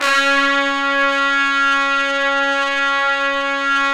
Index of /90_sSampleCDs/Roland LCDP06 Brass Sections/BRS_Tpts mp)f/BRS_Tps Velo-Xfd